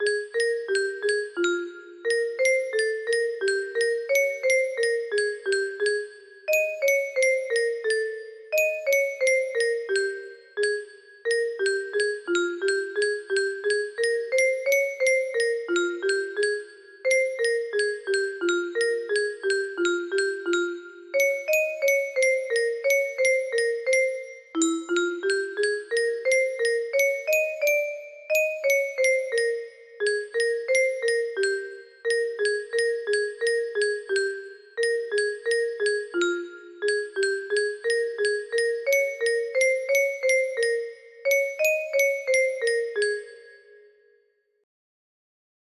A World without You music box melody
Key: Ab major
Tempo: 88
Time sig: 3/4